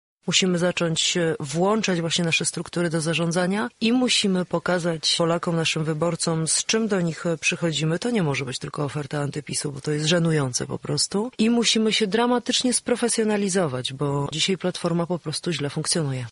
Była minister sportu w Porannej Rozmowie Radia Centrum stwierdziła, że „należy skończyć z wodzowskim stylem zarządzania partią”: